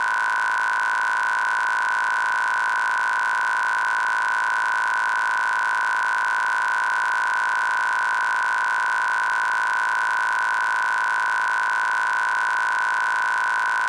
Меандр 55 Гц в режиме ЧМ с разносом 500 Гц
55hz_fm.wav